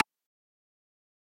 keyboard1.mp3